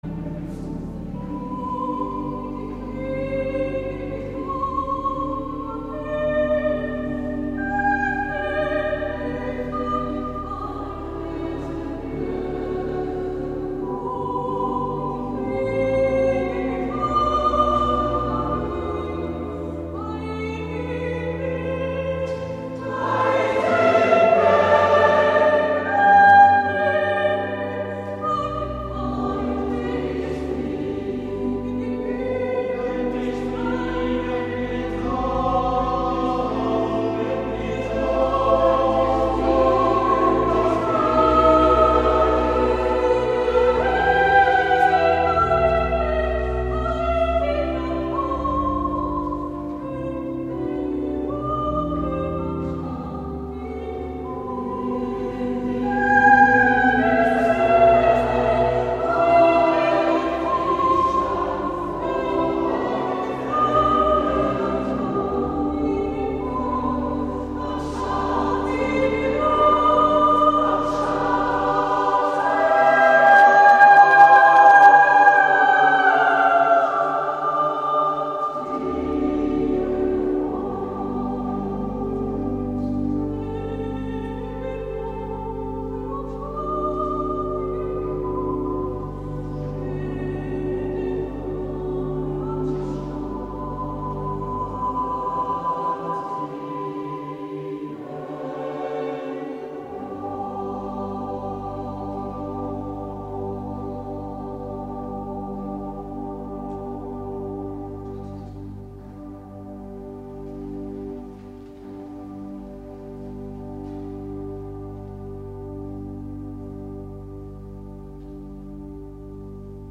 Kirchenchor
In unserem Kirchenchor singen ca. 30 Sängerinnen und Sänger.